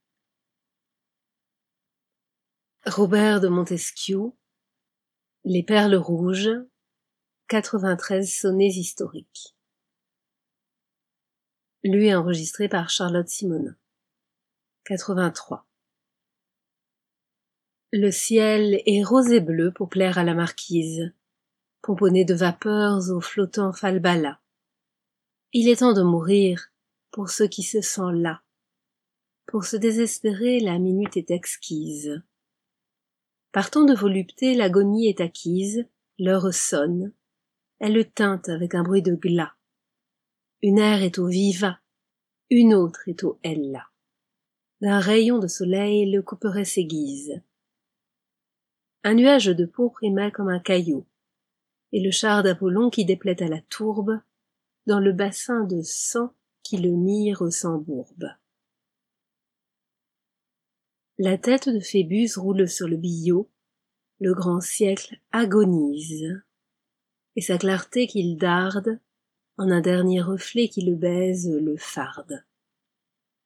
lu par